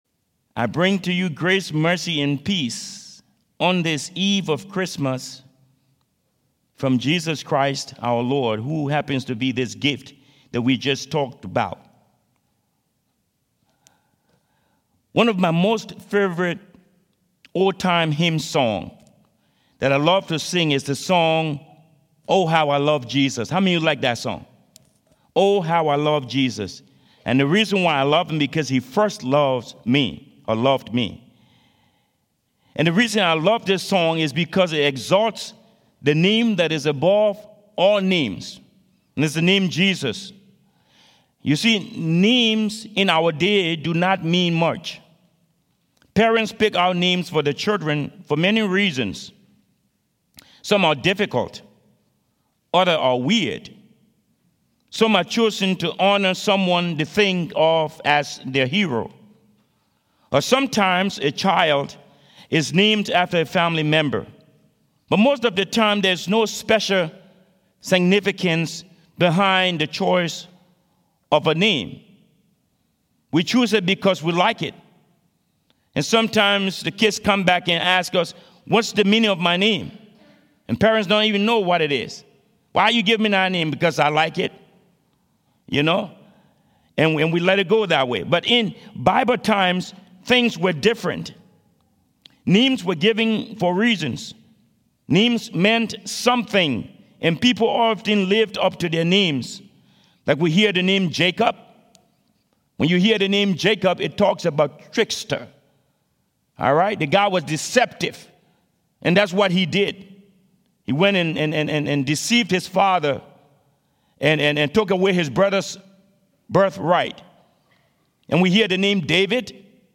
Sermon prepared by the Holy Spirit